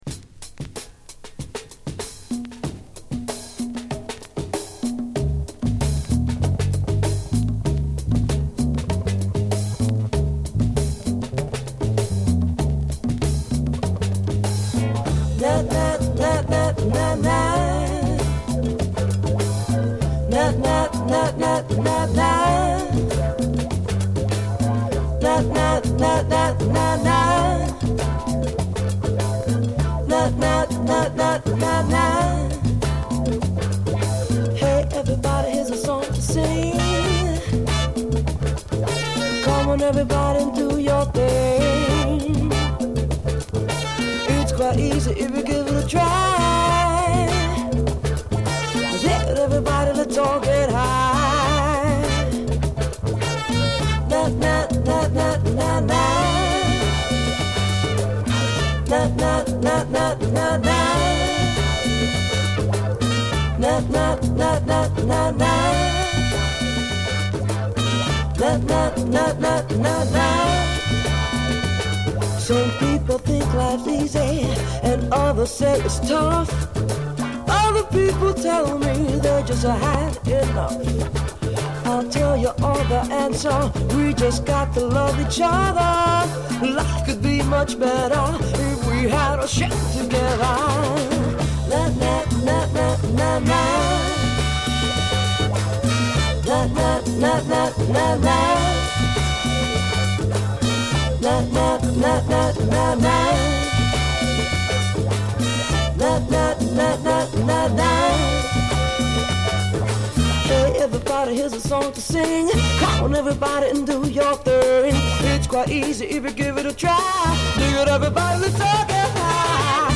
straight up Latin heat